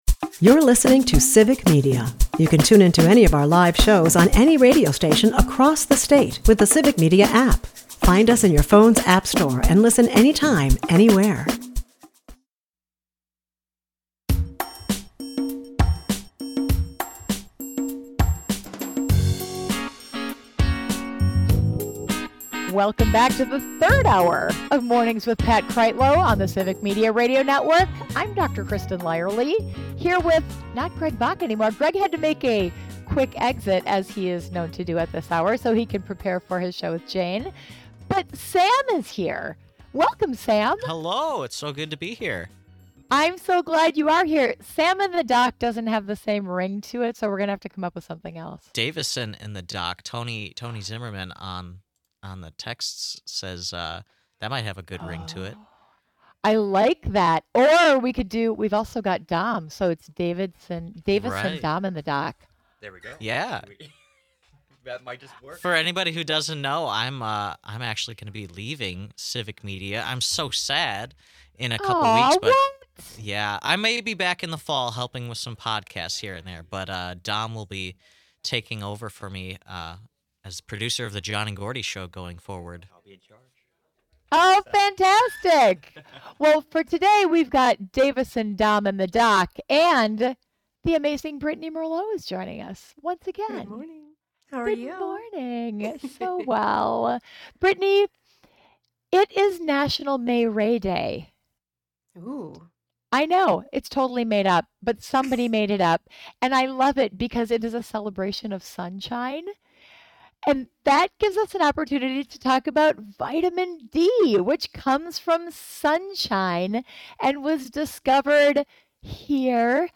The episode kicks off with weather updates promising a sunny Memorial Day weekend, perfect for grilling and outdoor fun.